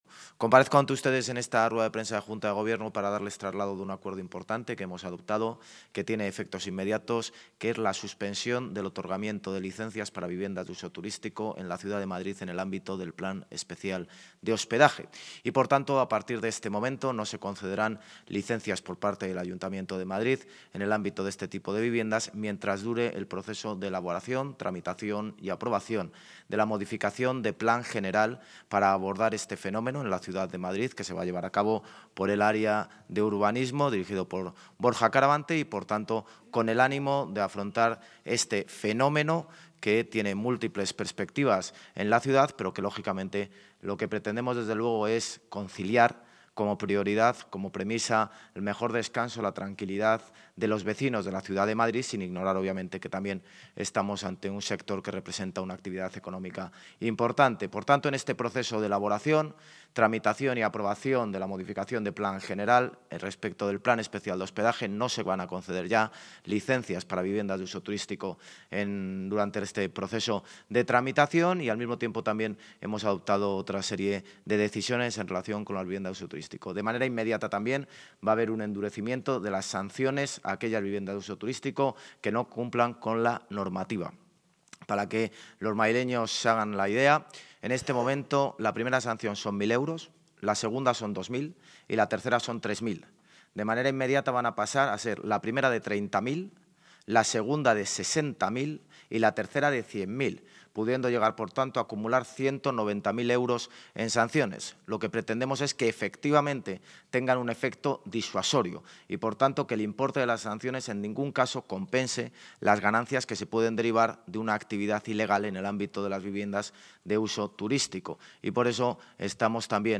El alcalde de Madrid, José Luis Martínez-Almeida, ha anunciado hoy en rueda de prensa posterior a Junta de Gobierno que el Ayuntamiento de la capital pone en marcha, desde hoy, un plan de acción para equilibrar el mercado de hospedaje en la ciudad que contempla diversas acciones, entre las que se encuentran:
AUDIO-Jose-Luis-Martinez-Almeida-Junta-de-Gobierno-Plan-accion-viviendas-uso-turistico.mp3